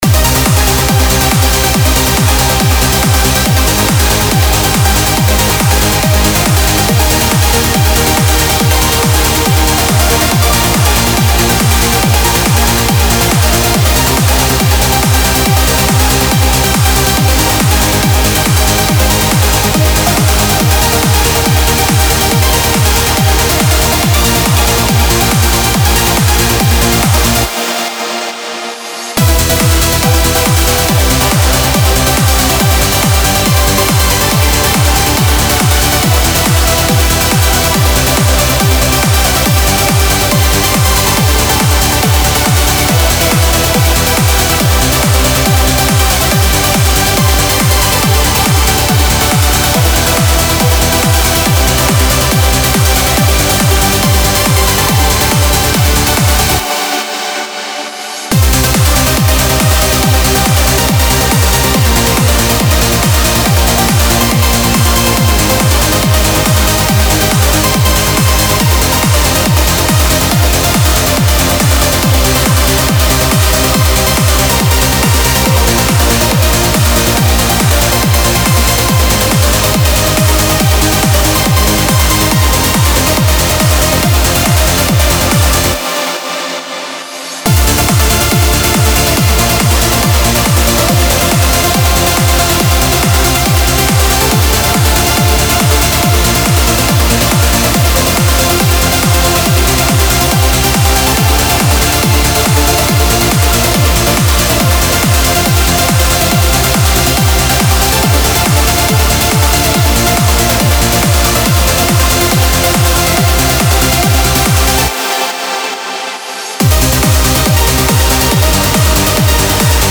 Trance Uplifting Trance
15 Trance Melody Kits
15 x Main Melody
15 x Bass Line
(Preview demo is 140 BPM)
Style: Trance, Uplifting Trance